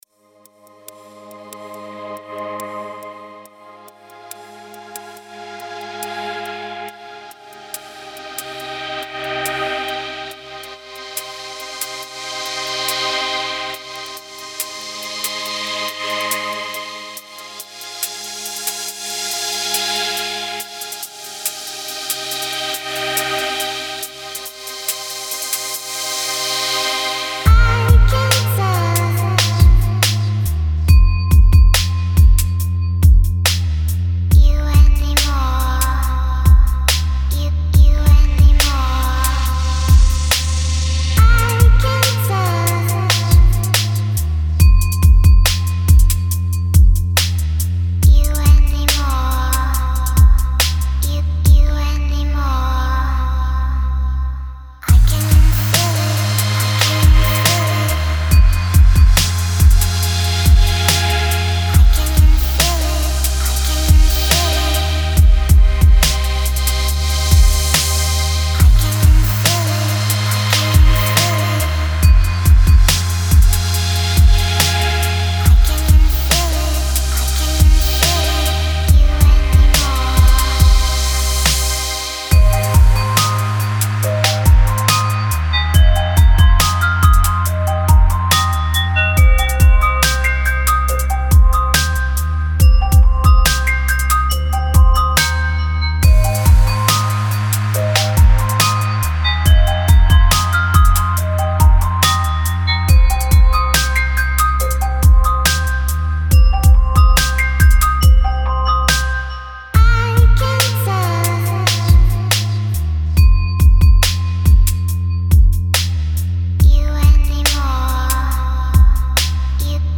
Жанр: Trance